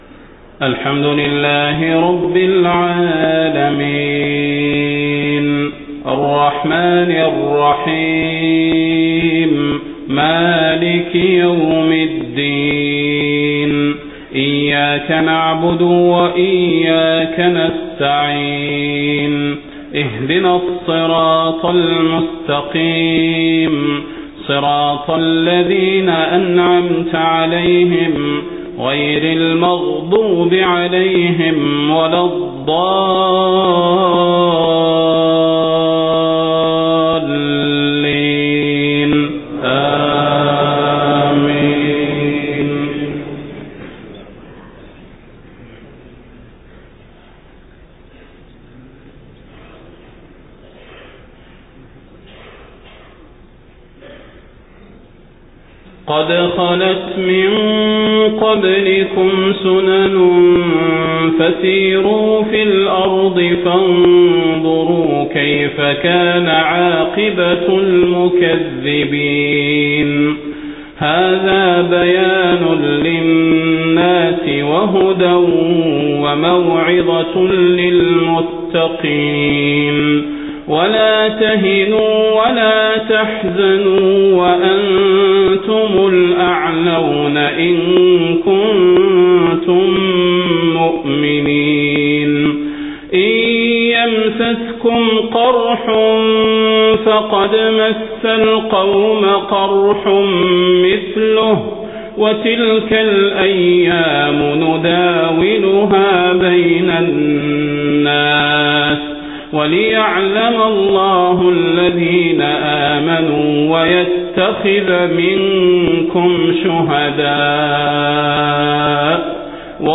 صلاة المغرب 20 محرم 1430هـ من سورة آل عمران 137-148 > 1430 🕌 > الفروض - تلاوات الحرمين